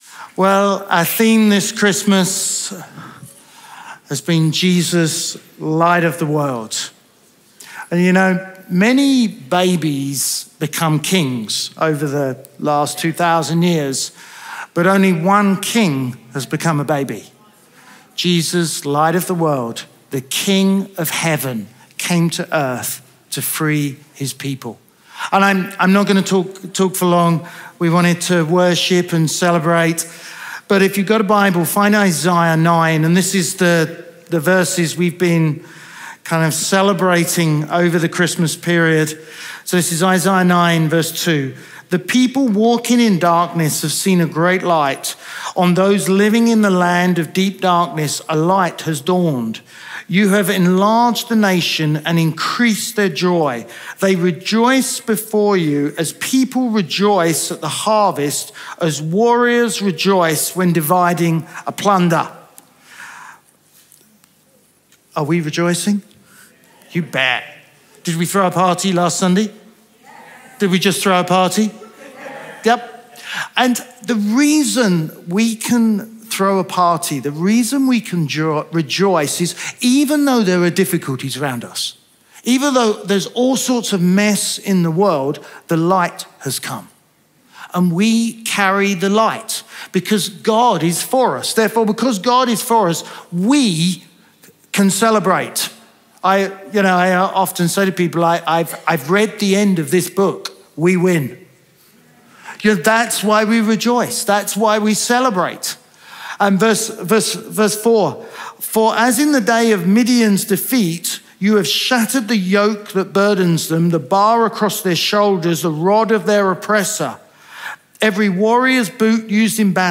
Chroma Church - Sunday Sermon Growing in Wisdom, Statue of Favour Dec 20 2022 | 00:21:13 1x 00:00 / 00:21:13 Subscribe Share RSS Feed Share Link Embed